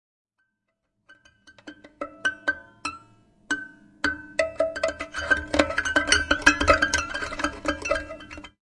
描述：吉他琶音和弦乐。用Ibanez空心电吉他演奏。
Tag: 吉他 爵士乐 民谣 弹奏 电影音乐 器乐